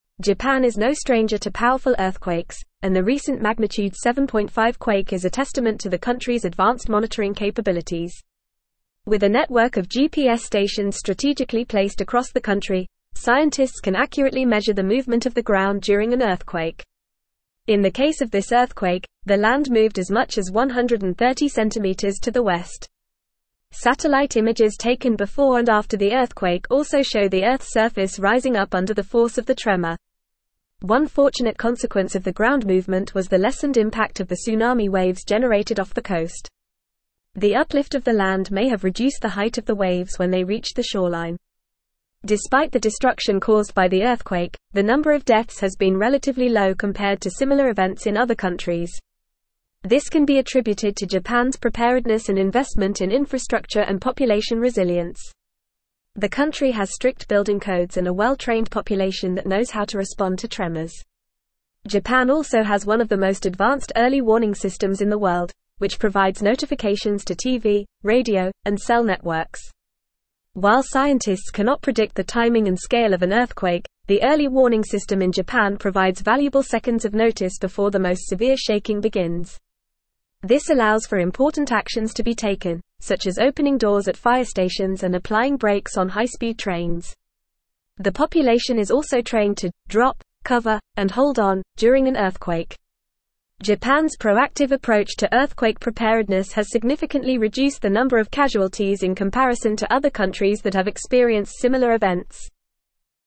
Fast
English-Newsroom-Advanced-FAST-Reading-Japans-Earthquake-Preparedness-Low-Death-Toll-High-Resilience.mp3